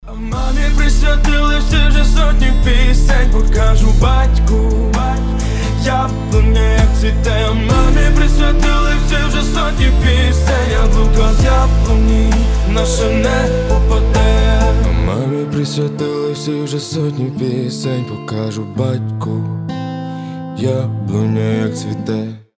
• Качество: 320, Stereo
гитара
душевные